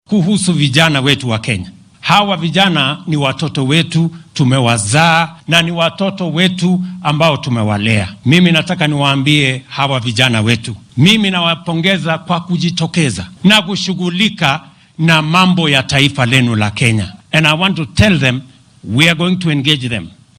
Xilli uu munaasabad kaniiseed uga qayb galayay magaalada Nyahururu ee ismaamulka Laikipia ayuu hoggaamiyaha qaranka hoosta ka xarriiqay inuu ku faanaya sida dhallinyarada ay si wayn ugu soo baxeen adeegsiga xaqooda dimuqraadiyadeed.